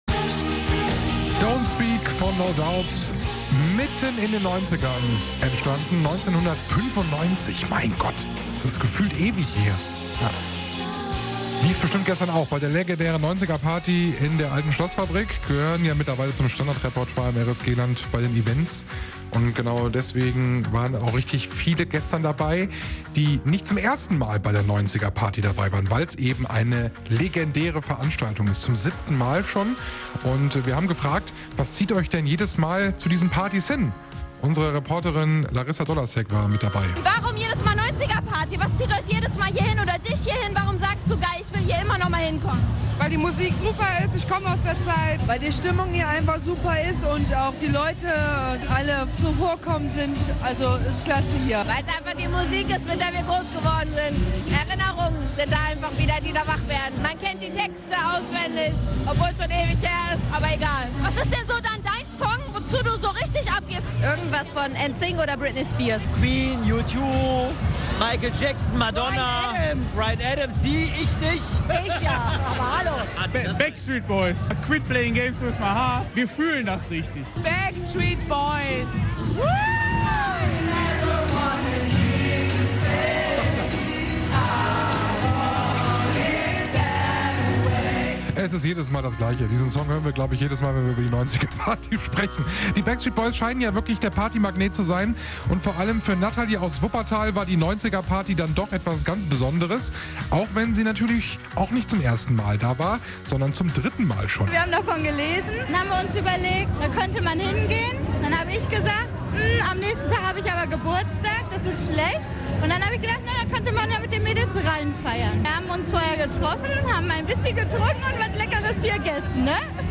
RSG-WochenendeDas war die 90er Party - Umfrage 2